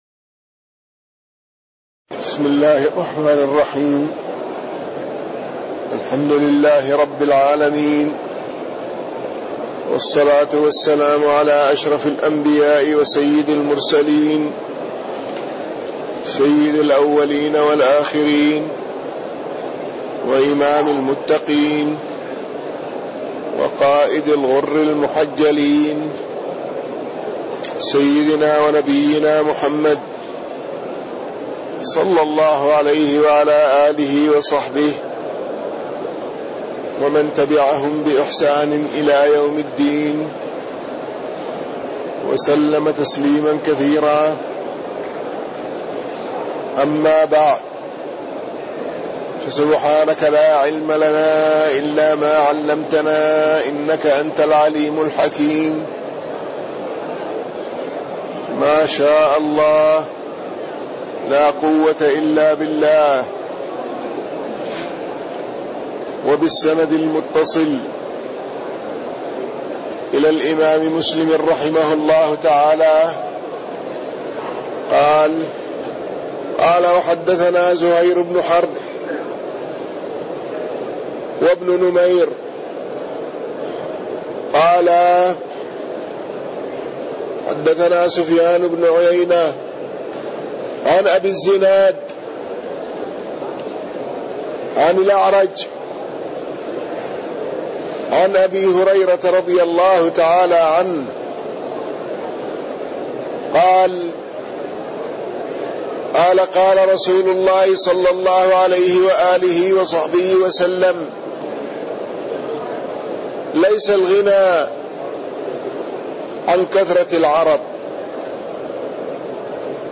تاريخ النشر ٢٤ رمضان ١٤٣٢ هـ المكان: المسجد النبوي الشيخ